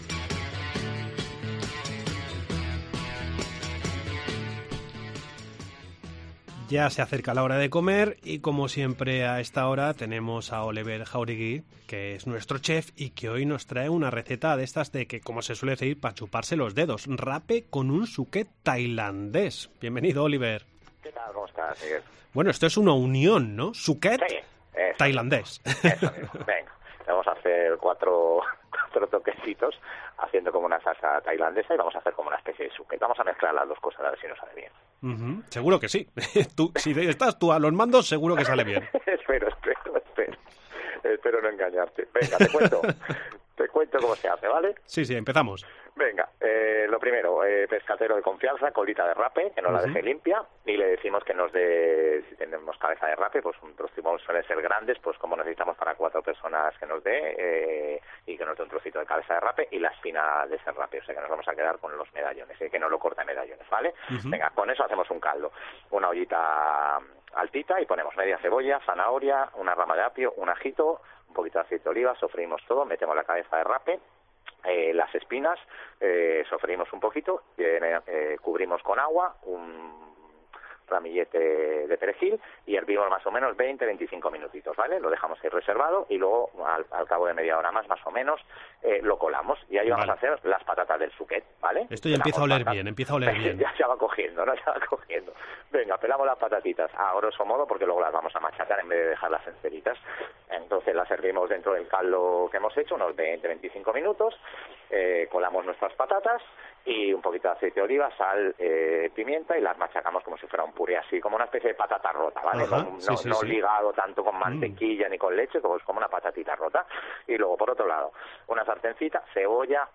Entrevista en La Mañana en Baleares, lunes 05 de diciembre 2022.